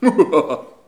mouhahaha_02.wav